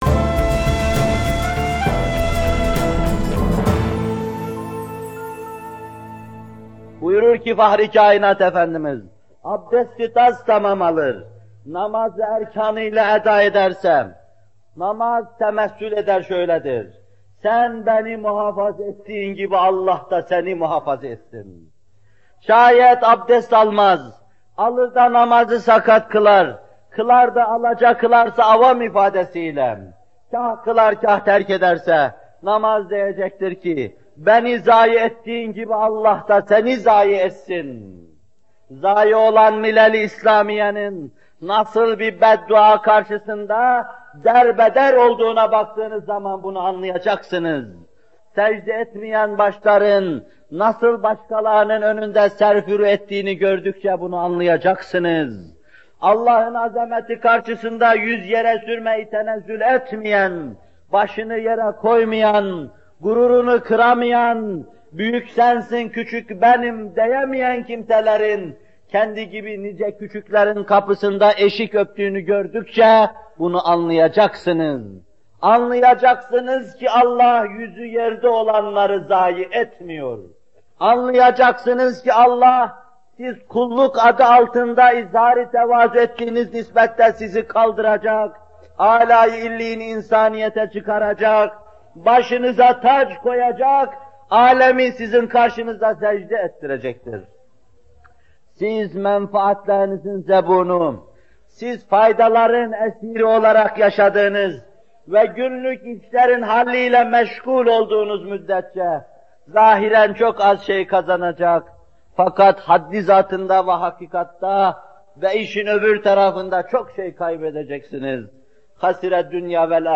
Bu bölüm Muhterem Fethullah Gülen Hocaefendi’nin 22 Eylül 1978 tarihinde Bornova/İZMİR’de vermiş olduğu “Namaz Vaazları 6” isimli vaazından alınmıştır.